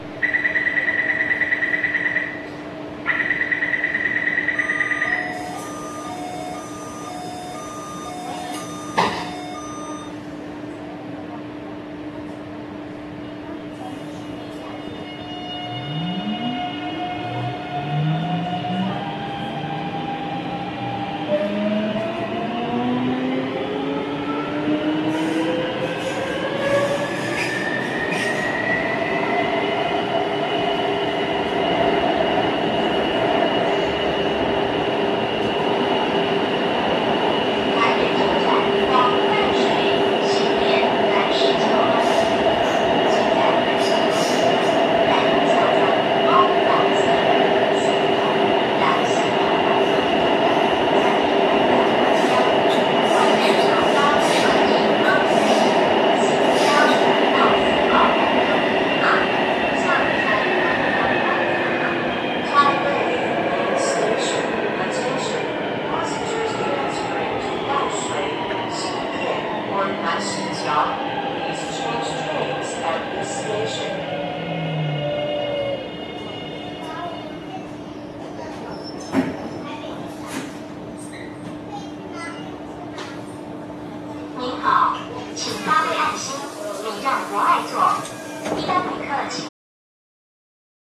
制御装置は車両製造と同じくSIEMENS製のGTO-VVVFインバータで、日本では聞けないパターンの変調音がします。
起動時はモーターからの唸りも大きく、迫力があります。
走行音（1170）
収録区間：南港線 善導寺→台北車站
音に何ら違いはありません。編成によって異音が入ったり、同期モードでの変調音が爆音気味なったりする個体差が結構あるように思えます。